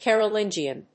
/ˌkɛrʌˈlɪndʒiʌn(米国英語), ˌkerʌˈlɪndʒi:ʌn(英国英語)/